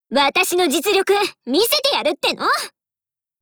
Cv-40307_warcry.wav